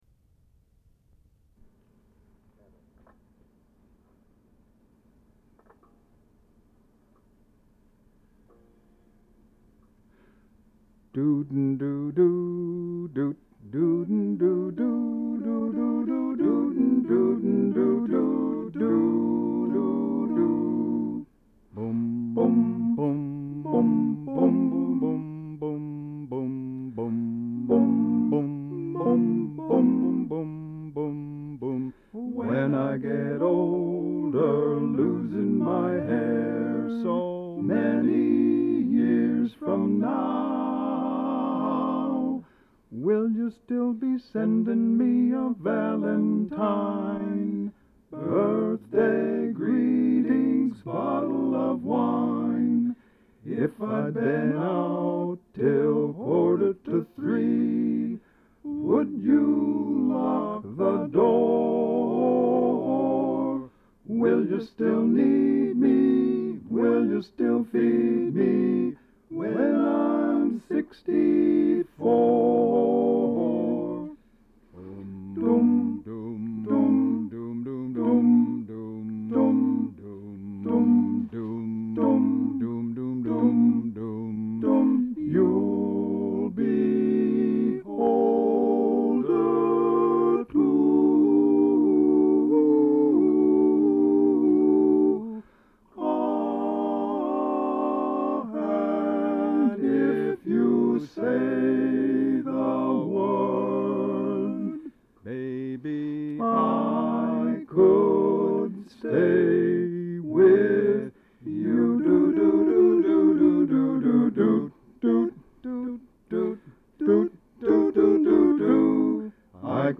as a quartet
singing with himself in four parts